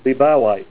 Help on Name Pronunciation: Name Pronunciation: Liebauite + Pronunciation